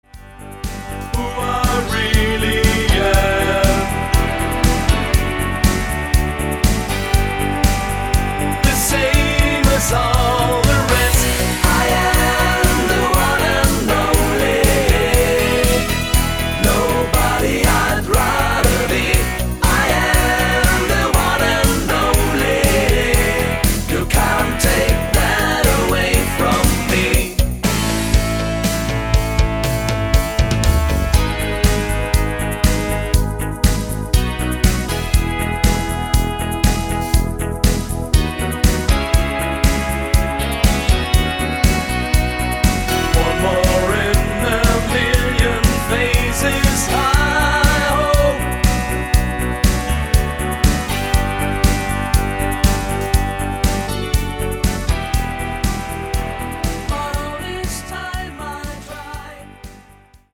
80er Kult Rockhit